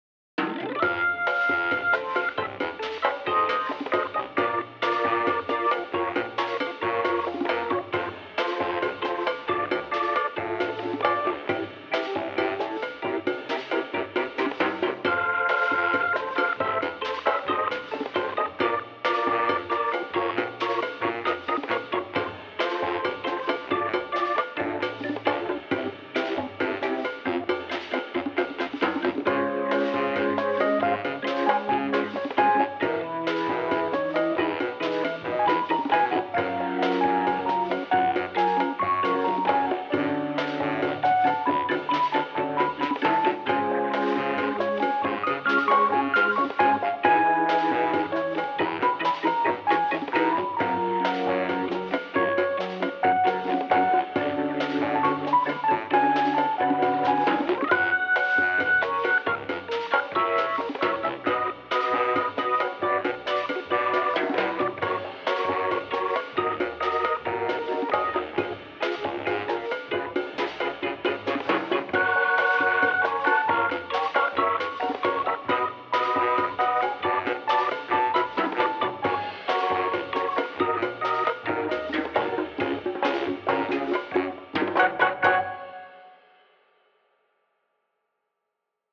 MP3 (EQ + Less Drums)